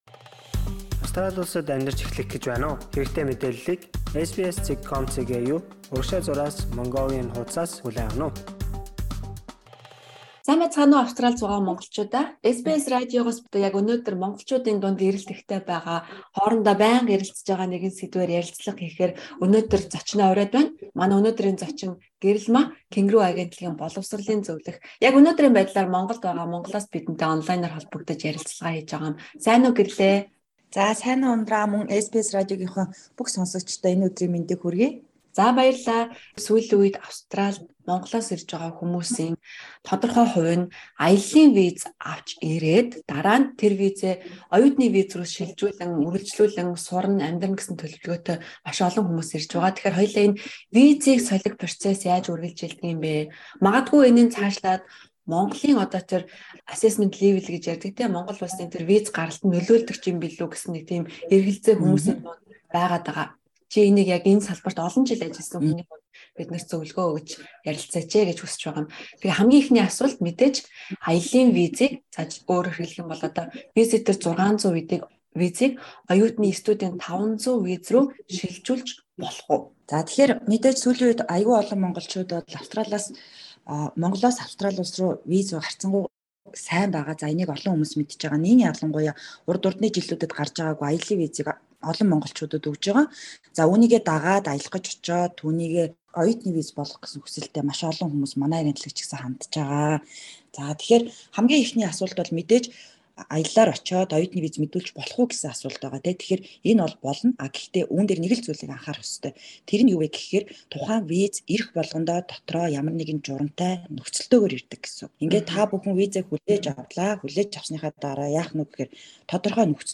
Боловсролын зөвлөх